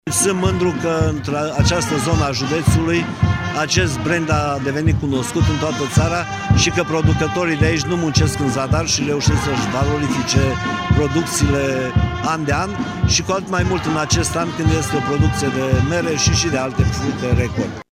A fost prezent la manifestare și prefectul de Mureș, Mircea Dușa: